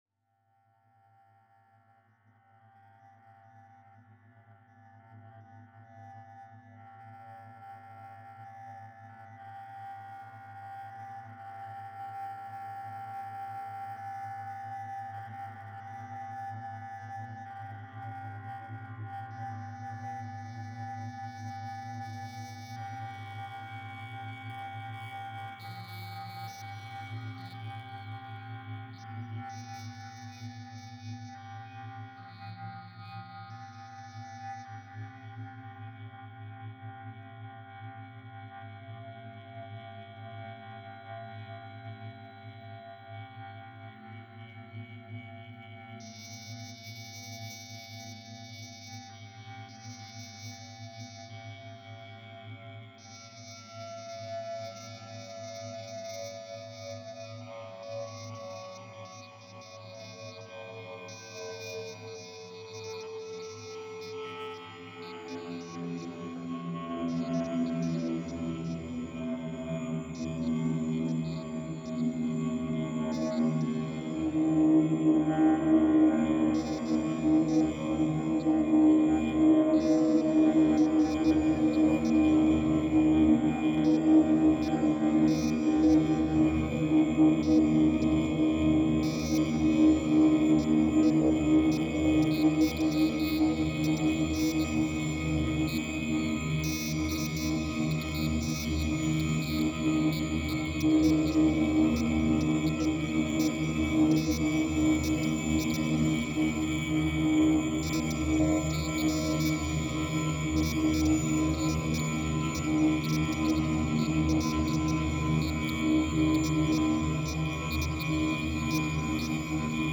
透明感溢れる艶やかな音響菌糸が縺れ合う。
魂を静かに浸食するデス・アンビエント。